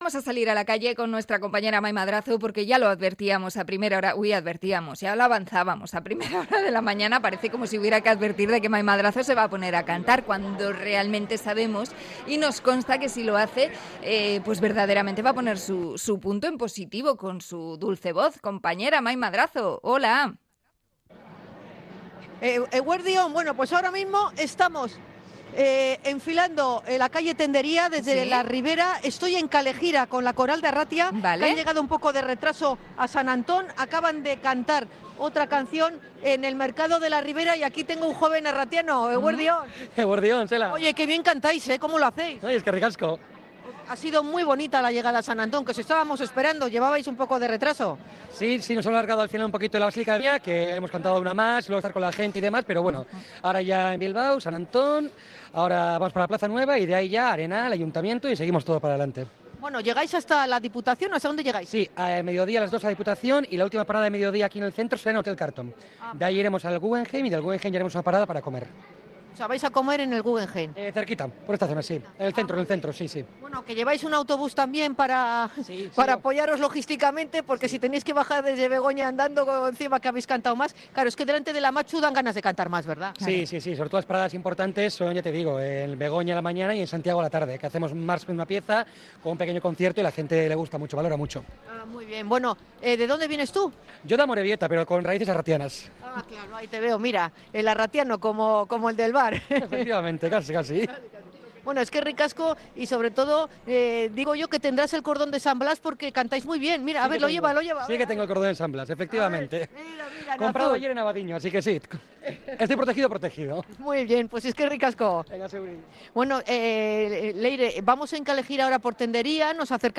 Cánticos, txistus y emoción: 75 años de la Coral de Arratia recorriendo Bilbao
Conexión desde el Casco Viejo con la coral de Arratia